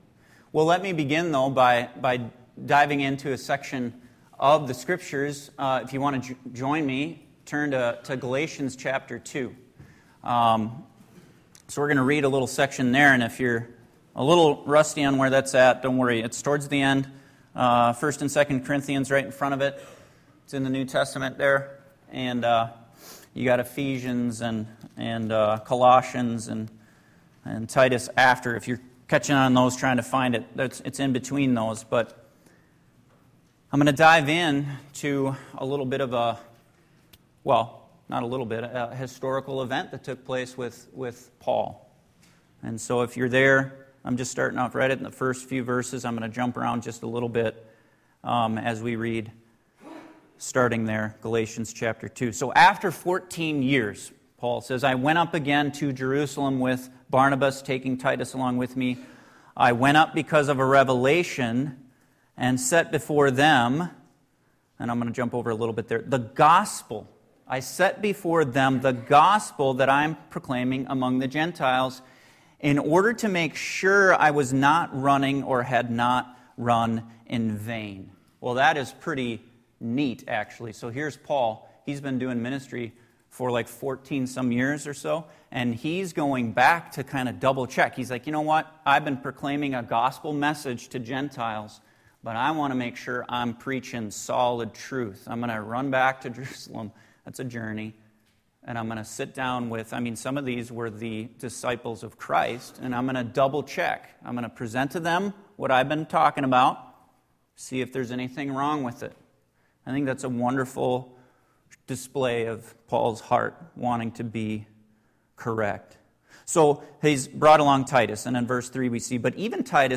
This is a biographical sermon about the life of George Whitfield, a powerful preacher in the 18th century during the first Great Awakening…